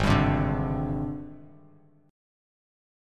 Listen to AbM#11 strummed